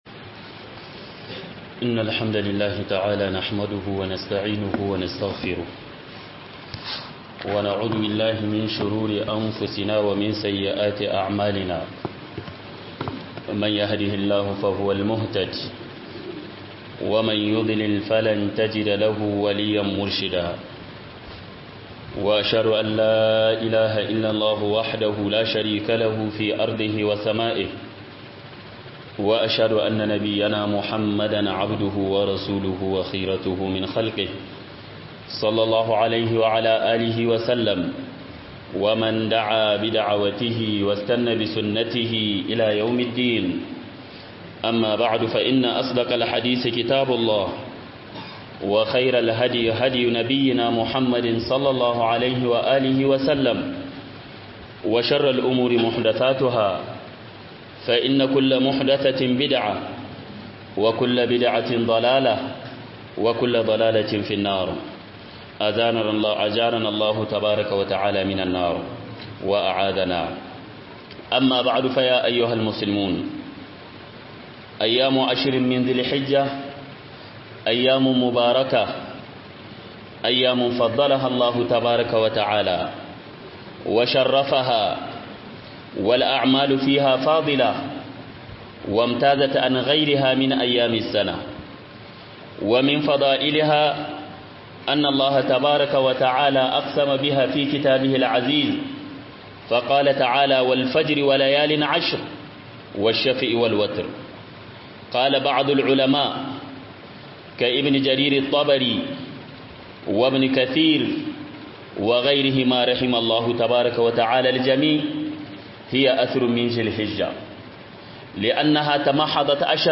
HDB - GOMA FARKON ZULHIJJA - HUDUBA